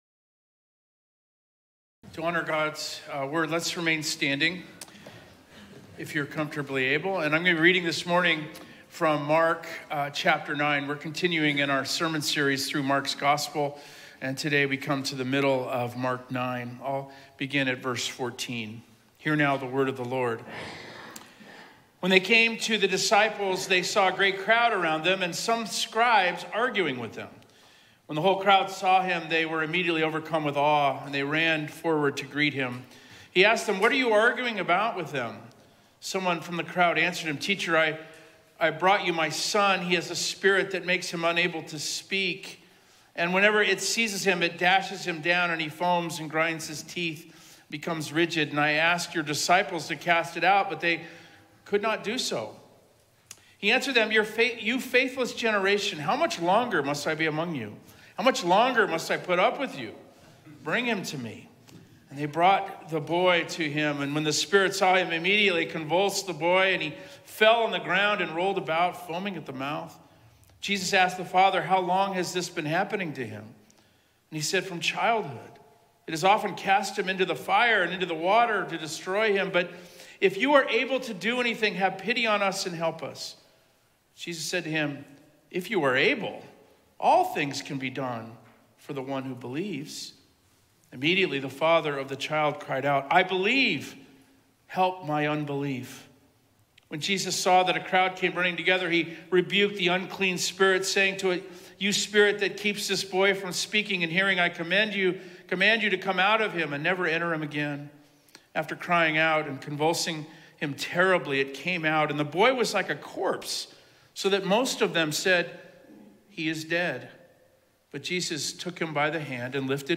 Sermons from Mountain View Presbyterian Church in Scottsdale, AZ